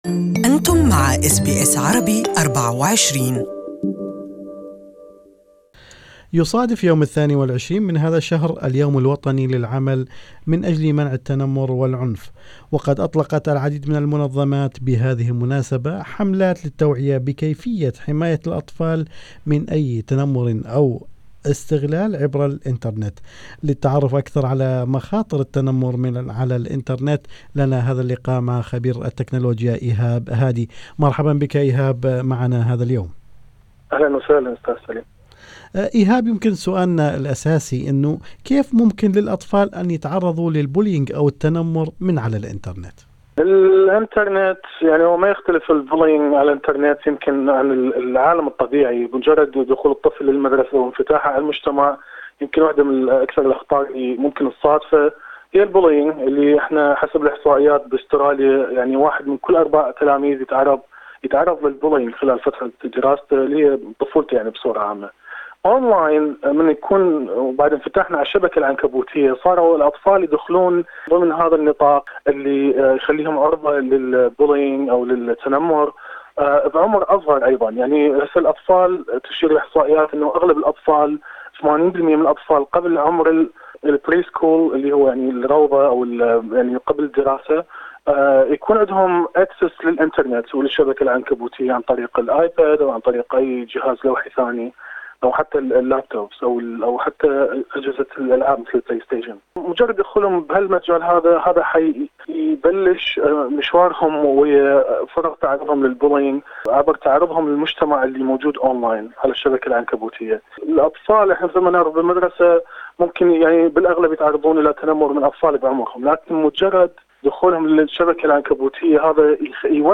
كان لنا هذا اللقاء مع خبير التكنلوجيا